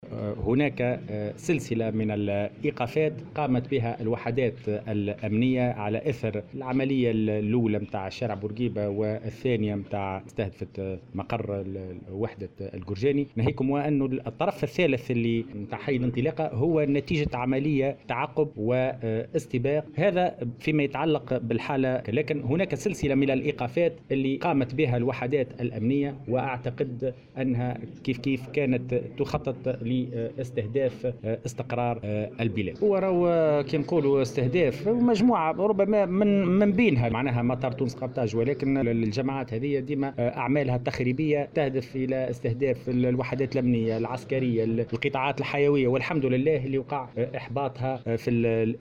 أعلن وزير الداخلية هشام الفوراتي اليوم الخميس 1 أوت 2019 خلال اشرافه على تنصيب والي القصرين الجديد محمد سمشة عن ايقاف عدد من العناصر الإرهابية التي كانت تخطط لإستهداف إسقرار البلاد من بينها مطار تونس قرطاج ، و ذلك على اثر العمليتين التفجيريتين بشارع الحبيب بورقيبة والقورجاني .